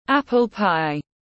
Bánh táo tiếng anh gọi là apple pie, phiên âm tiếng anh đọc là /ˌæp.əl ˈpaɪ/
Apple pie /ˌæp.əl ˈpaɪ/